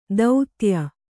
♪ dautya